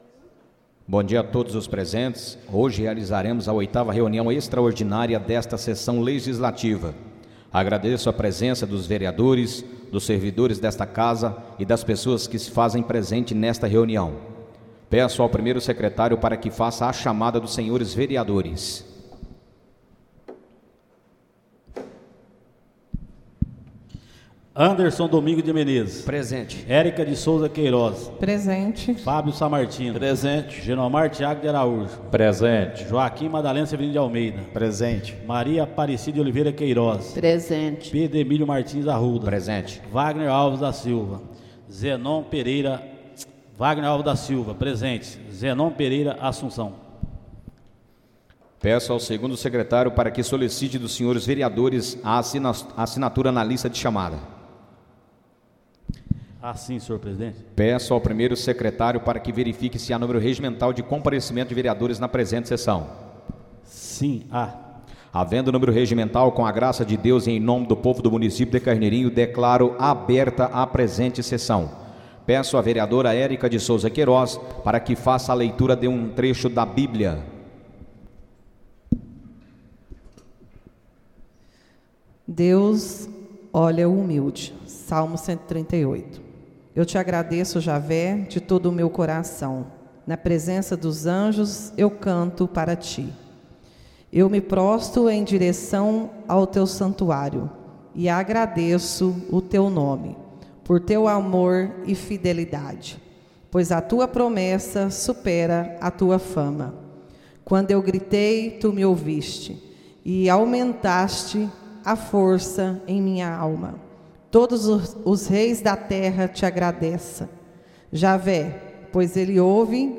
Áudio da 8.ª reunião extraordinária de 2024, realizada no dia 29 de Outubro de 2024, na sala de sessões da Câmara Municipal de Carneirinho, Estado de Minas Gerais.